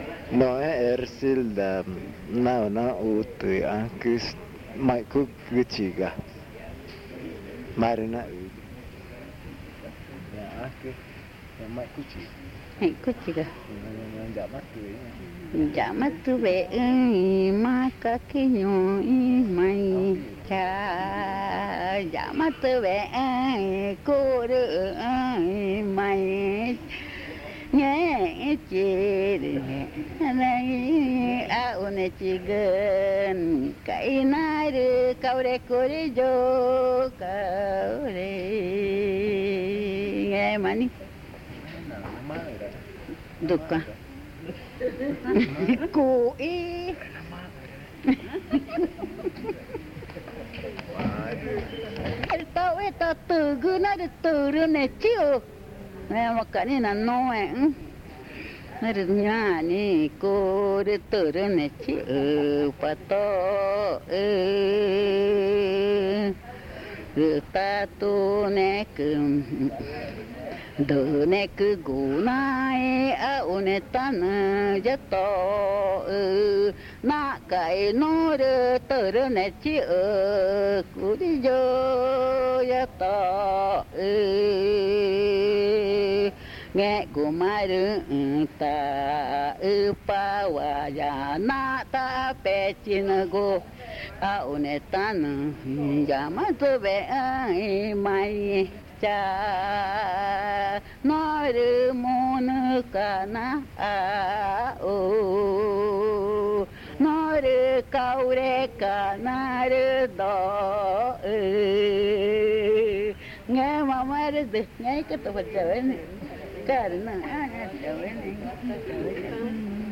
Canto del mico
Pozo Redondo, Amazonas (Colombia)